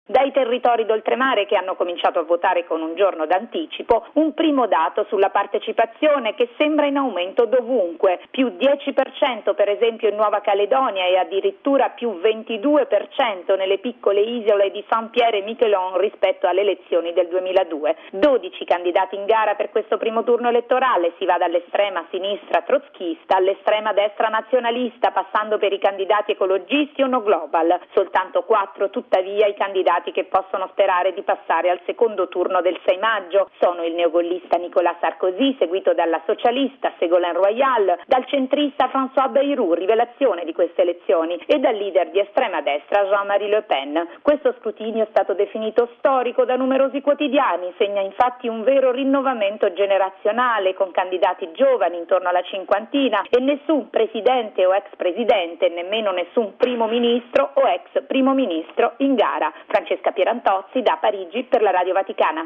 Da Parigi